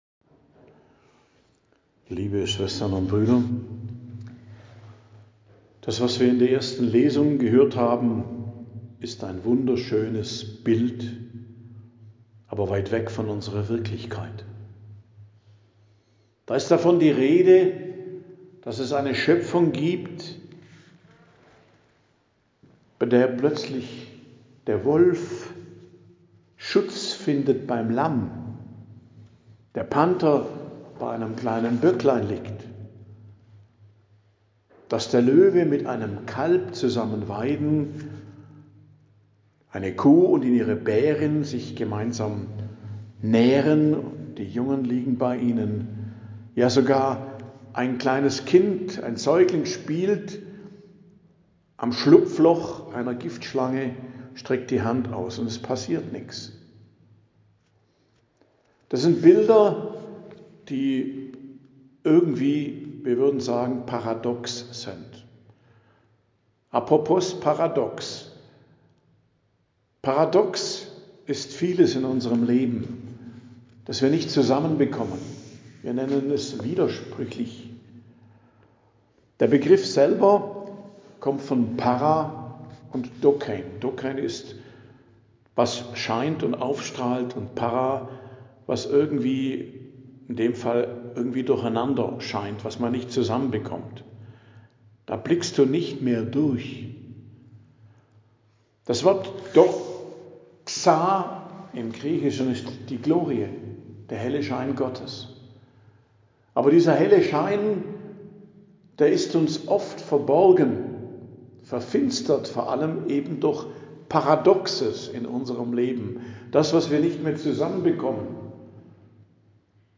Predigt am Dienstag der 1. Woche im Advent, 2.12.2025